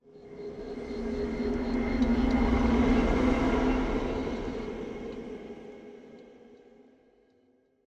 SFX / Ambient / Forest